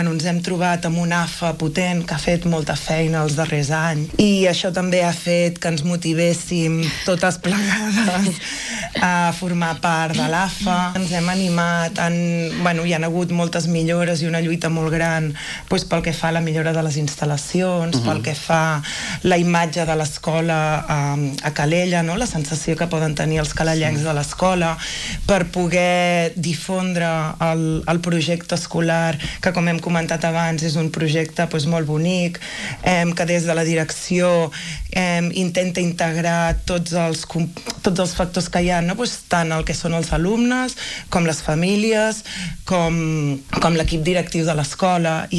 han passat aquest dilluns pel matinal de RCT per compartir la seva experiència després de dos mesos de curs.